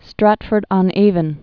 (strătfərd-ŏn-āvən, -ôn-) also Strat·ford-up·on-Av·on (-ə-pŏn-, -ə-pôn-)